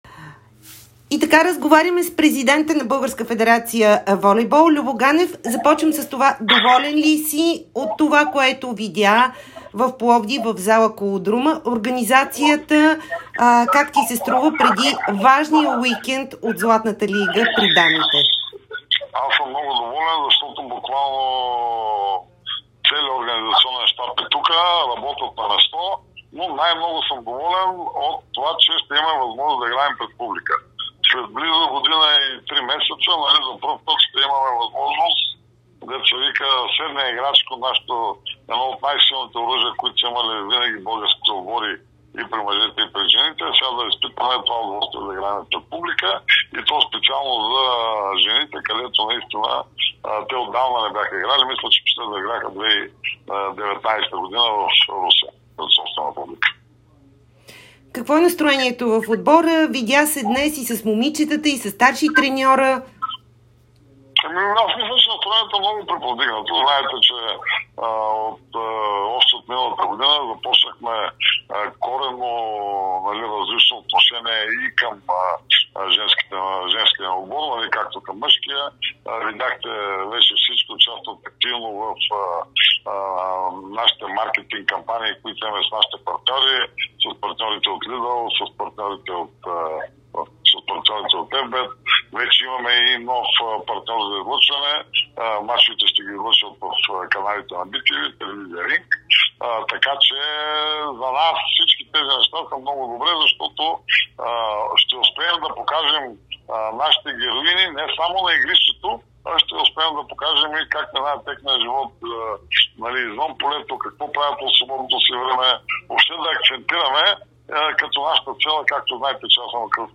Президентът на българската федерация по волейбол Любо Ганев даде интервю за Дарик и dsport, в което говори за представянето на мъжкия тим в Лигата на нациите, която се провежда в Римини и домакинството в Пловдив при жените в Златната европейска лига.